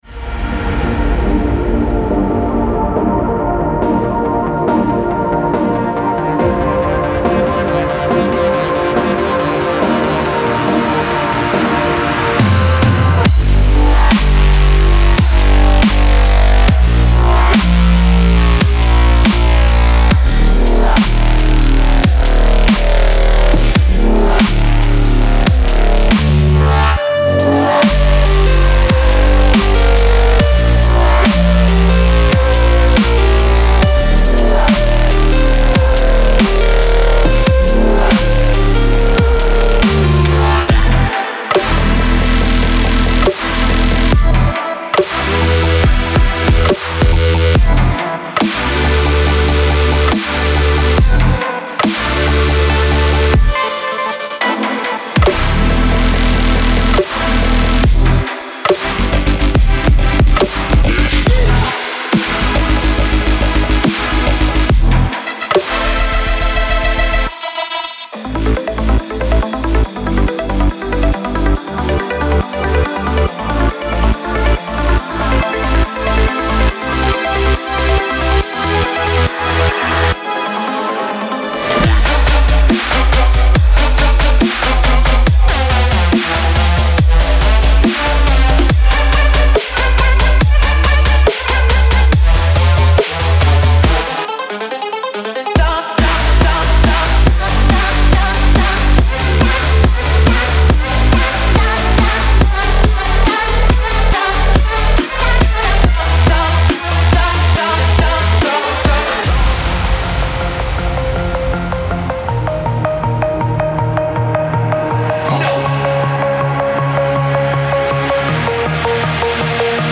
DubstepEDMFuture Bass
这个背包是一个破土动工，从笨重的新鲜休息到空灵的音景，再到带有肮脏边缘的深沉嗡嗡的低音线。
速度范围为90 – 160 BPM，并且可以在文件名中找到所有键和速度信息，以方便使用。